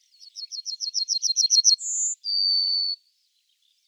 yellowhammer.mp3